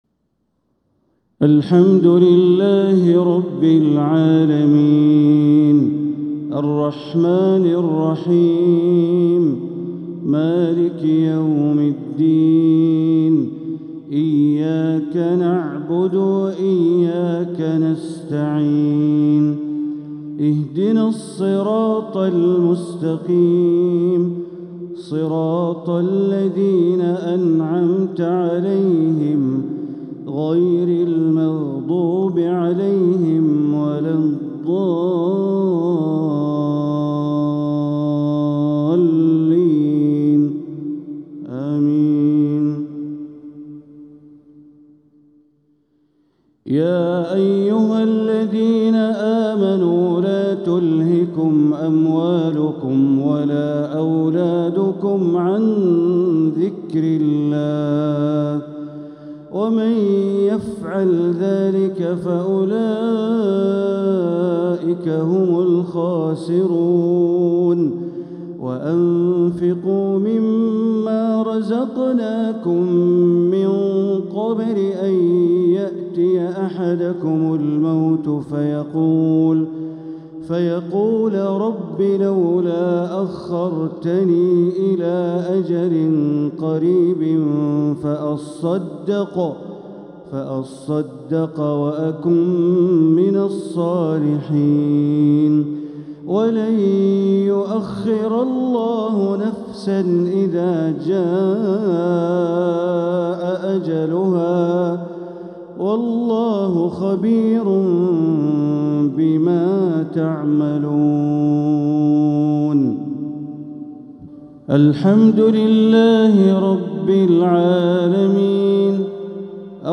تلاوة لآخر سورتي المنافقون والتغابن | عشاء الجمعة 1-4-1446هـ > 1446هـ > الفروض - تلاوات بندر بليلة